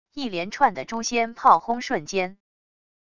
一连串的诛仙炮轰瞬间wav音频